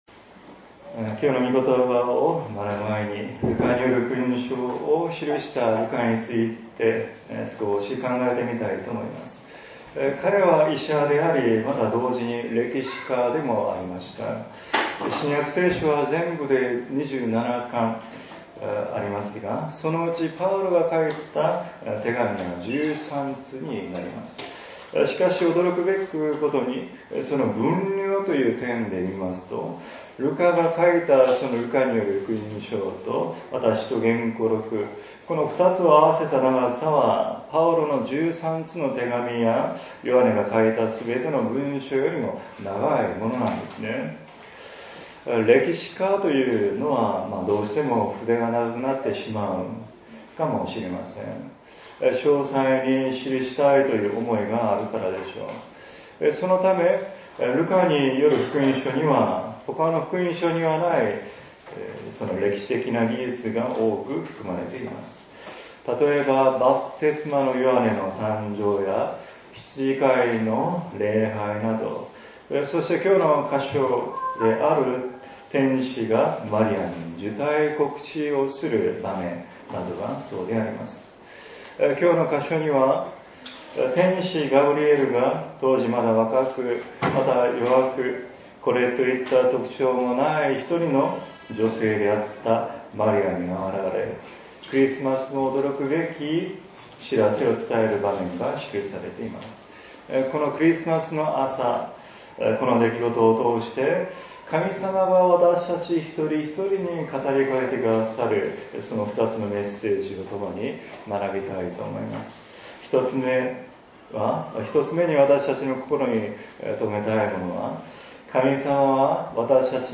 説 教 「神様の計画、マリアの従順」